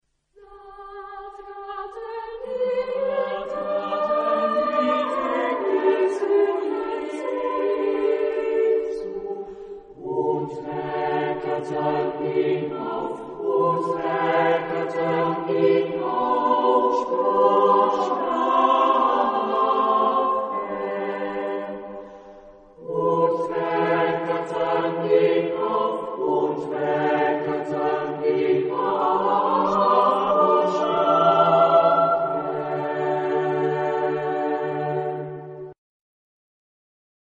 Genre-Stil-Form: Motette ; geistlich ; Barock
Chorgattung: SAT  (3 gemischter Chor Stimmen )
von Musica Nova gesungen